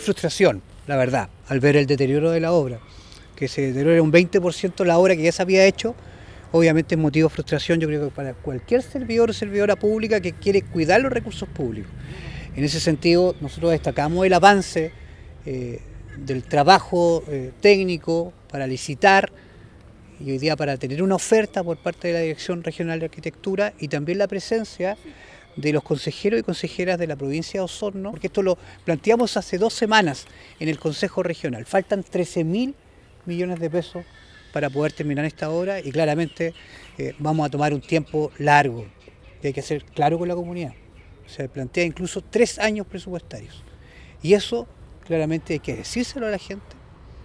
Por su parte, el Presidente de la Comisión de Obras en Conflicto, Francisco Reyes, manifestó su preocupación ante el actual estado de las obras del Liceo Carmela Carvajal, pues actualmente se requieren cerca de 13 mil millones para terminar los trabajos.